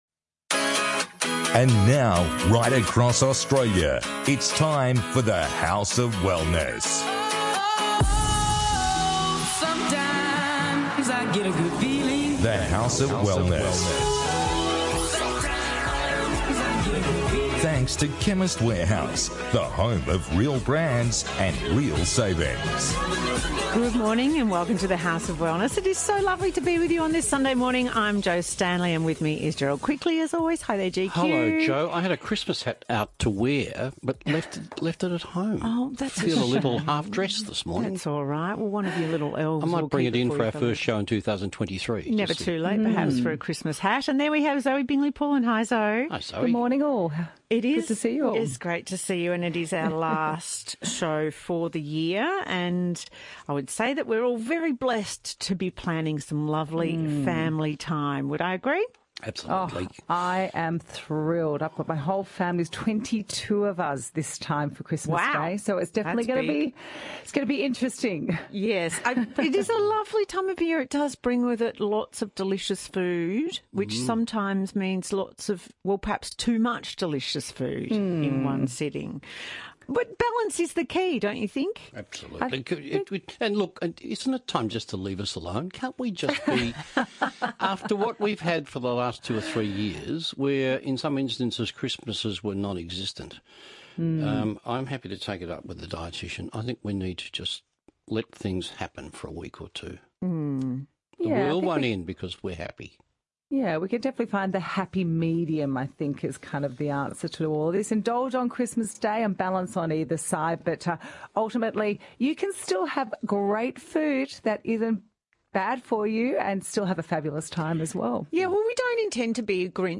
This week, the team discusses holiday feasting, summer pests, New Year’s Eve make-up and more.